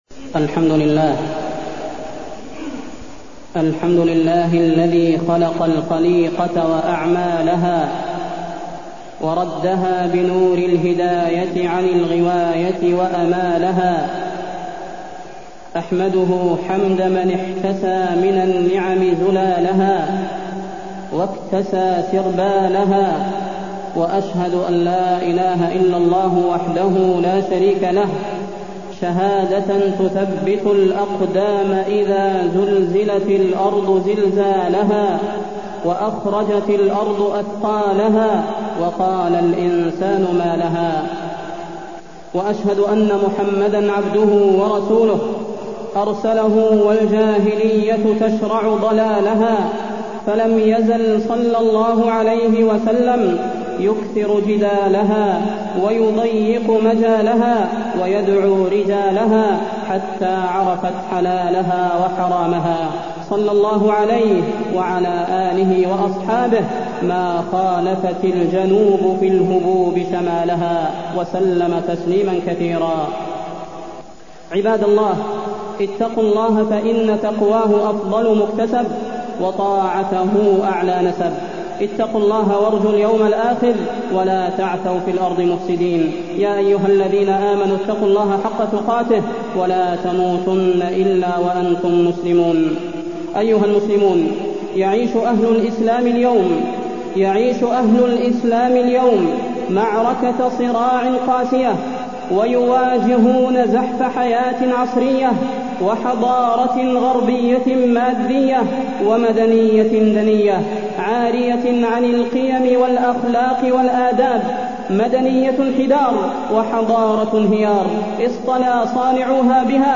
تاريخ النشر ١٣ جمادى الأولى ١٤٢٢ هـ المكان: المسجد النبوي الشيخ: فضيلة الشيخ د. صلاح بن محمد البدير فضيلة الشيخ د. صلاح بن محمد البدير الإسلام والمدنية الغربية